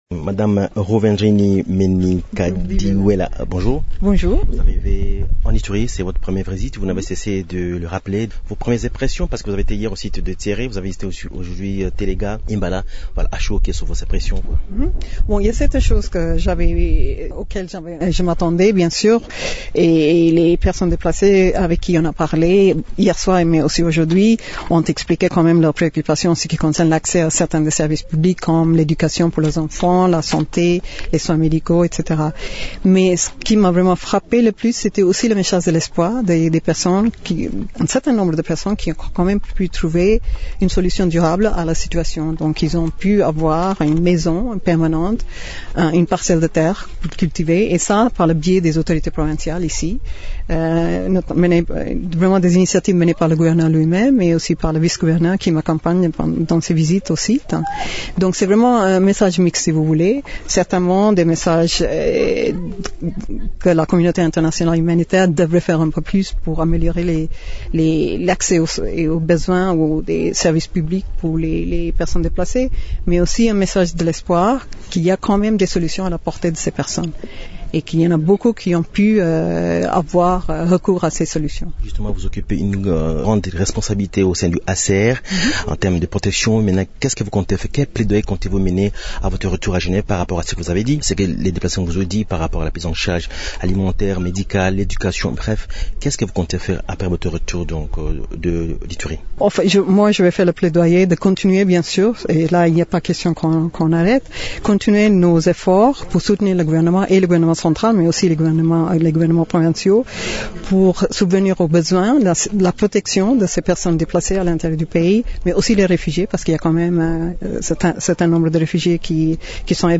Ruvendrini Menikdiwela est l’invitée de Radio Okapi ce vendredi 27 septembre.